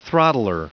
Prononciation du mot throttler en anglais (fichier audio)
Prononciation du mot : throttler